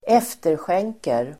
Ladda ner uttalet
efterskänka verb, remit (a debt) Grammatikkommentar: A & x Uttal: [²'ef:tersjeng:ker] Böjningar: efterskänkte, efterskänkt, efterskänk, efterskänka, efterskänker Definition: avstå från att kräva tillbaka (en skuld)